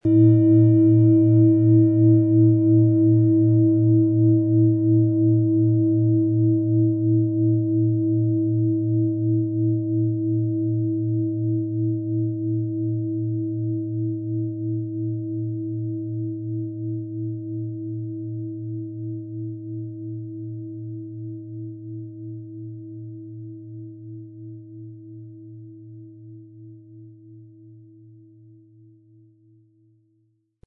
Tibetische Universal-Klangschale, Ø 22,9 cm, 1300-1400 Gramm, mit Klöppel
Der gratis Klöppel lässt die Schale wohltuend erklingen.
MaterialBronze